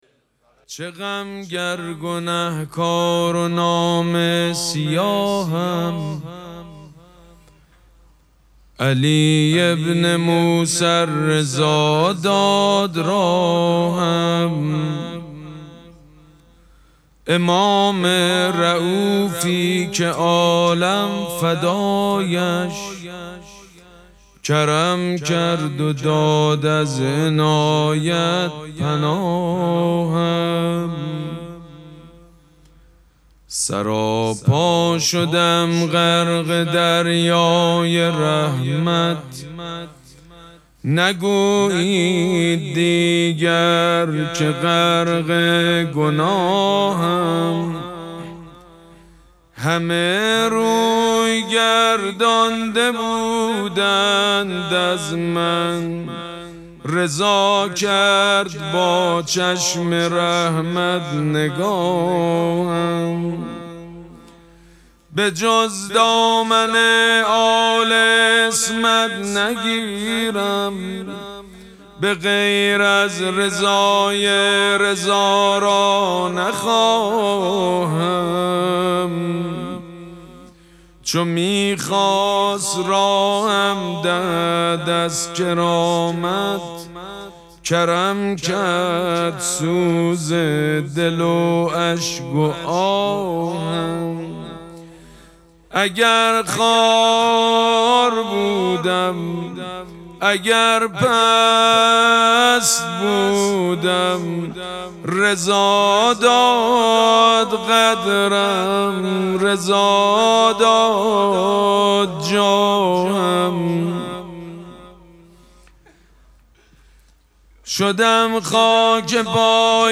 مراسم مناجات شب هشتم ماه مبارک رمضان شنبه ۱۸ اسفند ماه ۱۴۰۳ | ۷ رمضان ۱۴۴۶ حسینیه ریحانه الحسین سلام الله علیها
دانلود تصویر چه غم گر گنهکار و نامه سیاهم favorite مراسم مناجات شب هشتم ماه مبارک رمضان شنبه ۱۸ اسفند ماه ۱۴۰۳ | ۷ رمضان ۱۴۴۶ حسینیه ریحانه الحسین سلام الله علیها
مداح حاج سید مجید بنی فاطمه